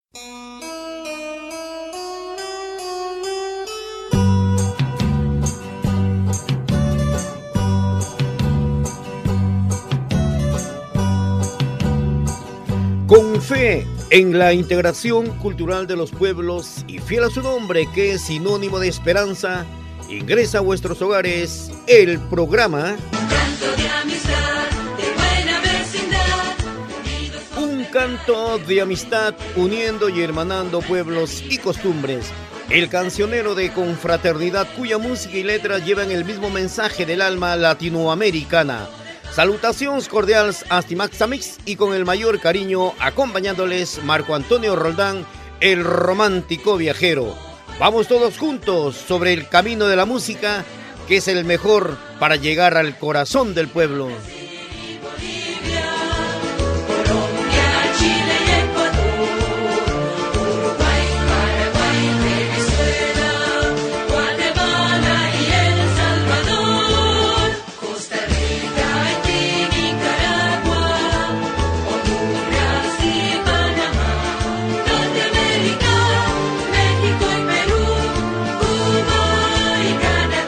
Presentació amb identificació del programa dedicat als espais naturals i la riquesa humana de Llatinoamèrica
FM
Fragment extret de l'arxiu sonor de COM Ràdio